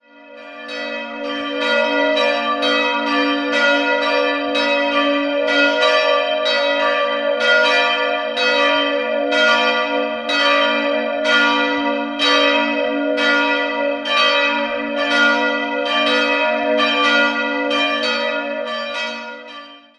Chor und Chorturm stammen noch aus spätgotischer Zeit. 2-stimmiges Große-Terz-Geläute: b'-d'' Die beiden Glocken wurden 1727 von Nikolaus und Alexander Arnoldt in Dinkelsbühl gegossen.